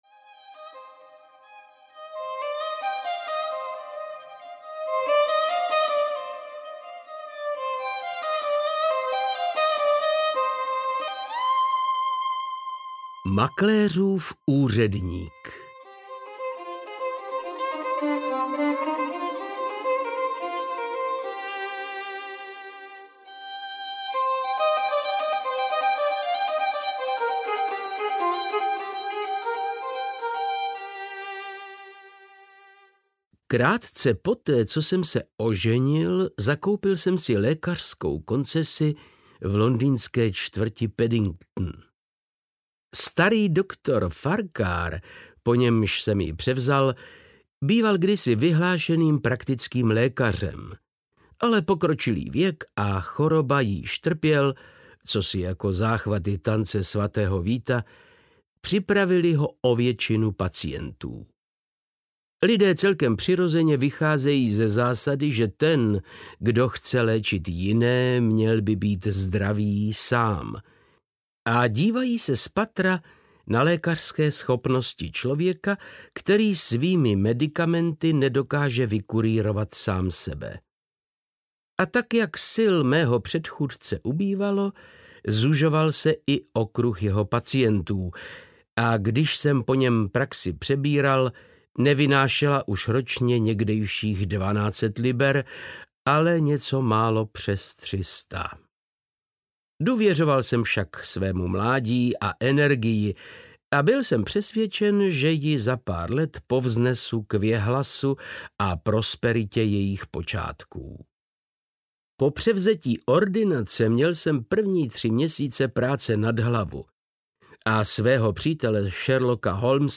• InterpretVáclav Knop
vzpominky-na-sherlocka-holmese-3-makleruv-urednik-audiokniha